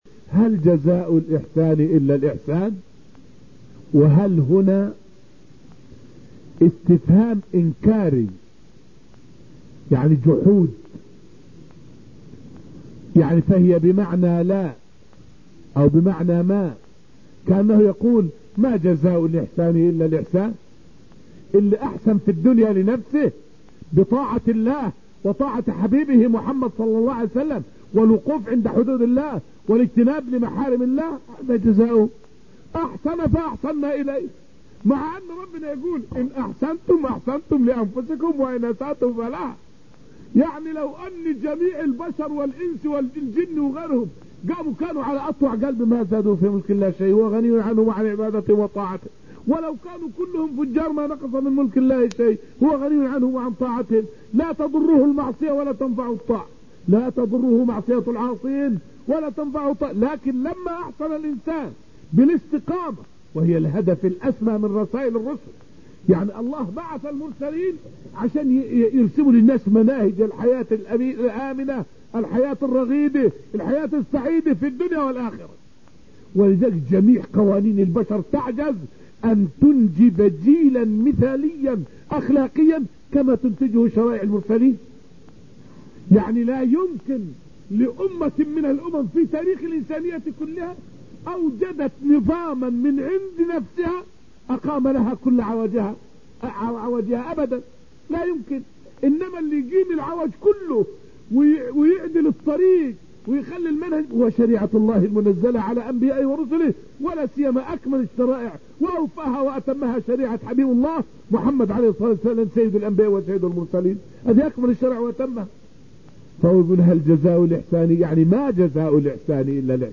فائدة من الدرس الثاني عشر من دروس تفسير سورة الرحمن والتي ألقيت في المسجد النبوي الشريف حول معنى {هل جزاء الإحسان إلا الإحسان}؟